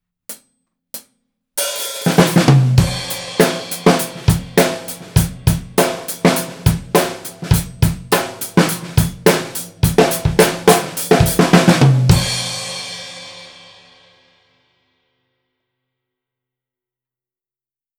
すべて、EQはしていません。
②ドラム目の前
続きまして、ドラムの目の前にマイキングしてみました。
個々の音がはっきり聞こえる様になってきましたね！
バランスがいい感じですね！